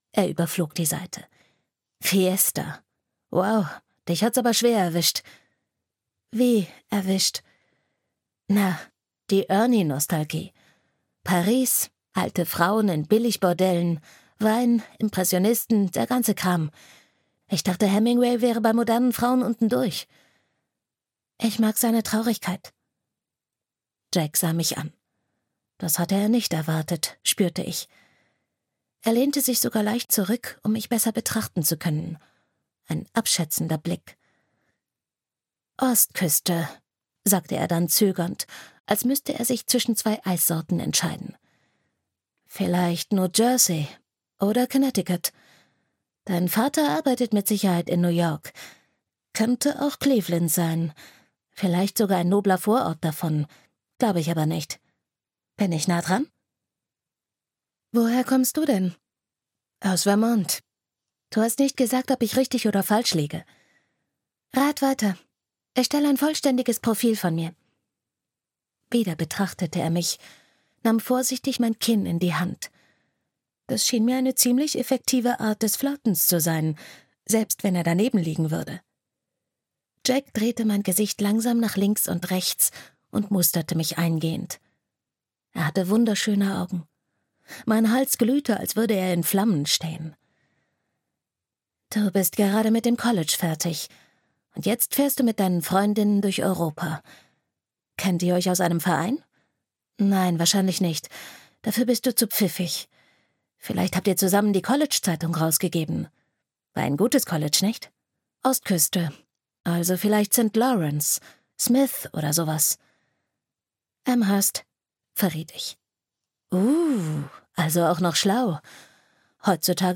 Liebe findet uns - J.P. Monninger - Hörbuch